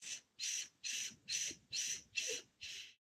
Minecraft Version Minecraft Version snapshot Latest Release | Latest Snapshot snapshot / assets / minecraft / sounds / mob / wolf / cute / panting.ogg Compare With Compare With Latest Release | Latest Snapshot
panting.ogg